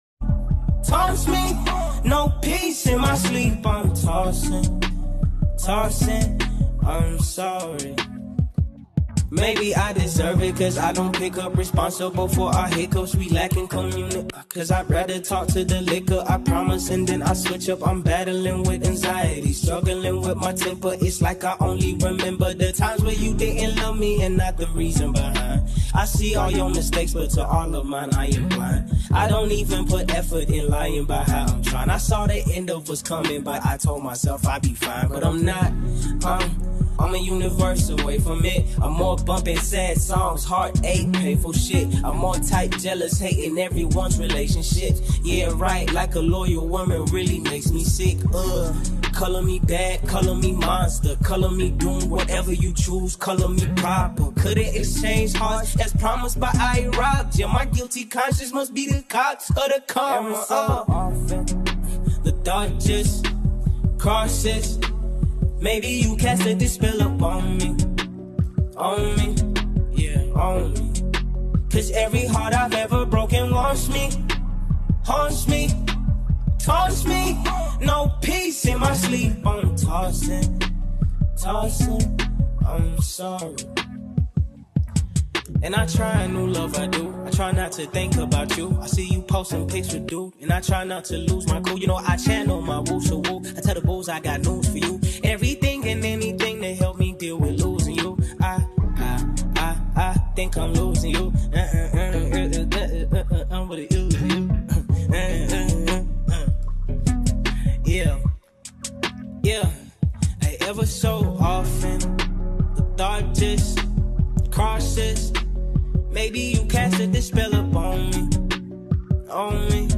South African sensational rapper